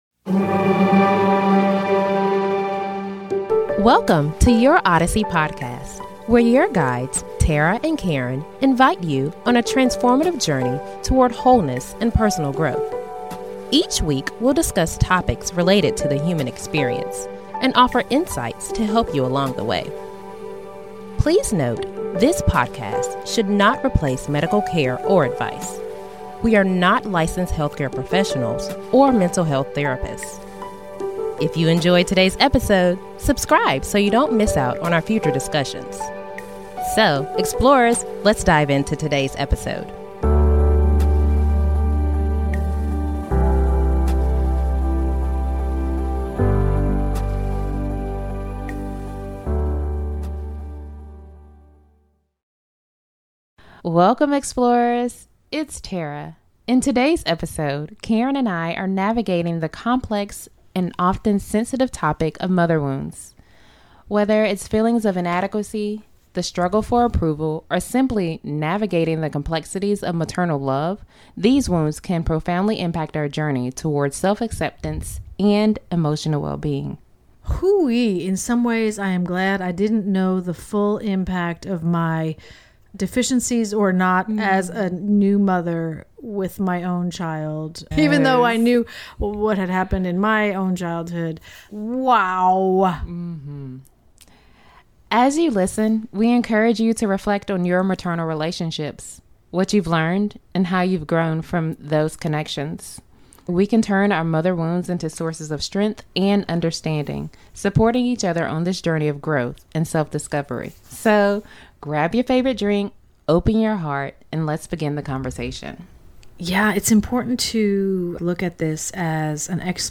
So grab your favorite drink and join the conversation